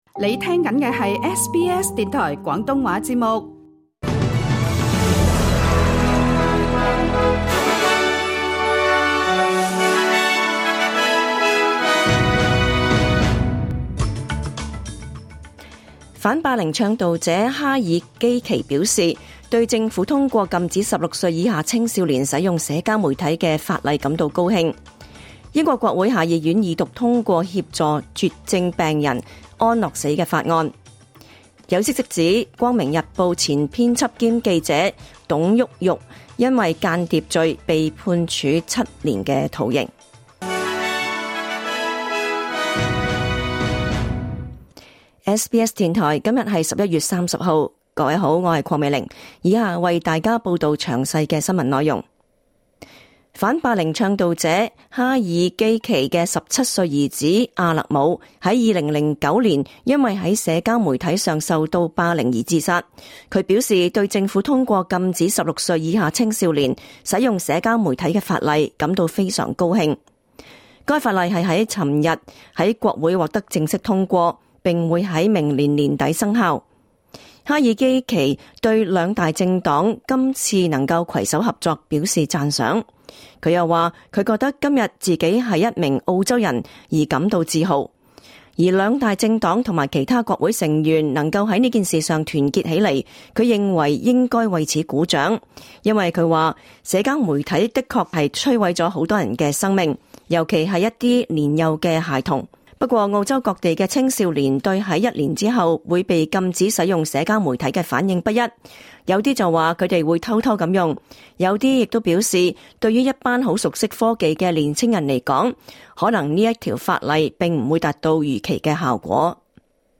2024 年 11 月 30 日 SBS 廣東話節目詳盡早晨新聞報道。